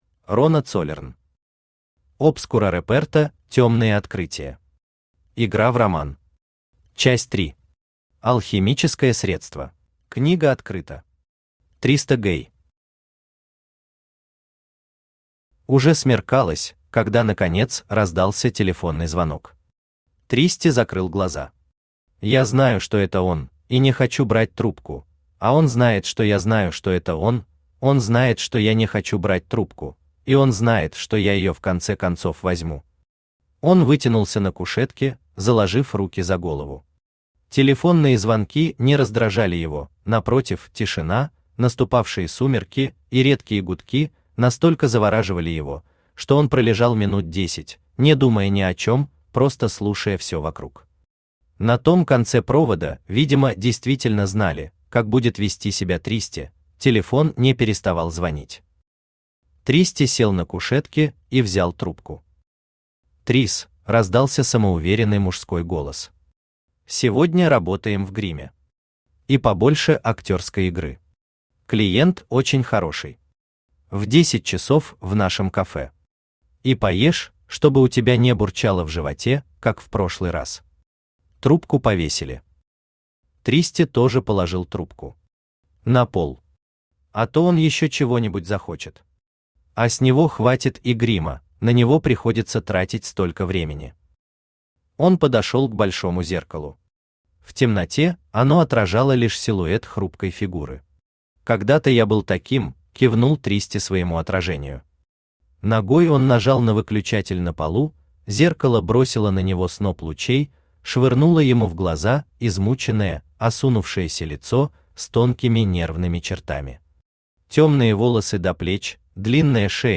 Аудиокнига Obscura reperta [Тёмные открытия]. Игра в роман. Часть 3. Алхимическое средство | Библиотека аудиокниг
Алхимическое средство Автор Рона Цоллерн Читает аудиокнигу Авточтец ЛитРес.